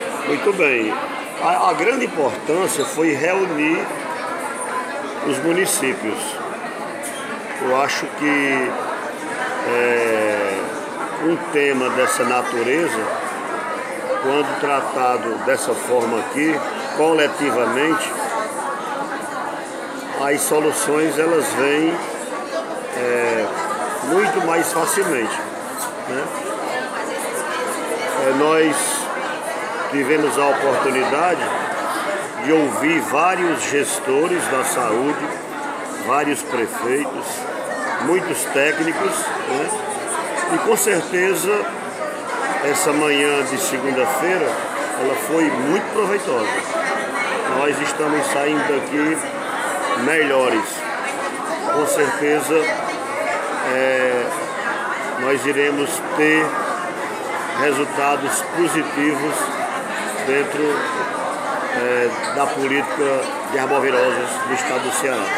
Sonora-Prefeito-Claudenilton-Pinheiro-Irapuan-Pinheiro.mp3